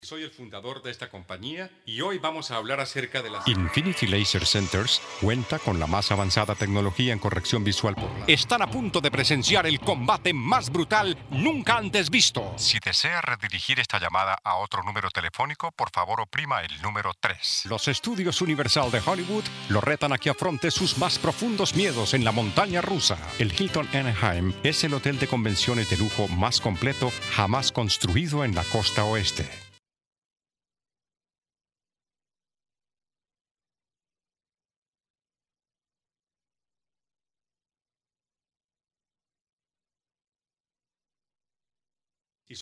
His elegant, multitalented and distinctive delivery is recognized as the very best in the industry.
spanischer Sprecher (Südamerika) voice-talent voice-over Colombian Native Locutor Neutro Colombiano
Sprechprobe: Industrie (Muttersprache):